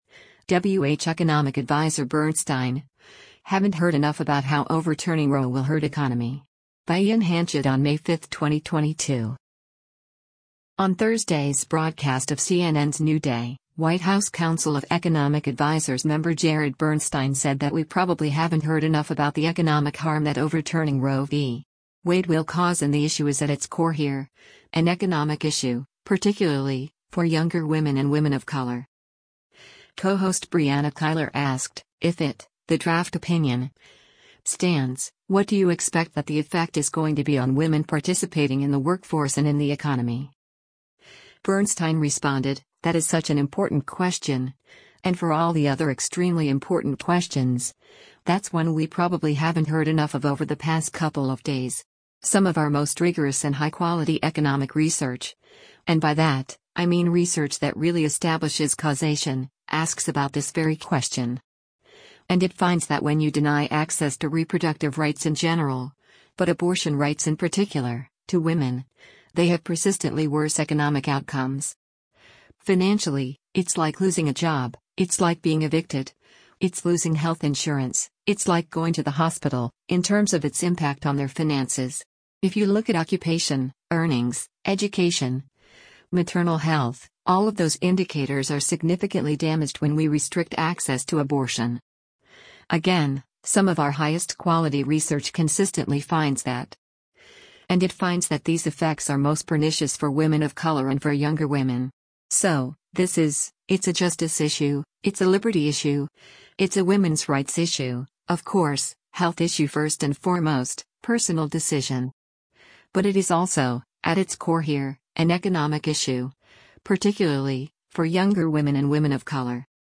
On Thursday’s broadcast of CNN’s “New Day,” White House Council of Economic Advisers member Jared Bernstein said that “we probably haven’t heard enough” about the economic harm that overturning Roe v. Wade will cause and the issue is “at its core here, an economic issue, particularly, for younger women and women of color.”
Co-host Brianna Keilar asked, “If it [the draft opinion] stands, what do you expect that the effect is going to be on women participating in the workforce and in the economy?”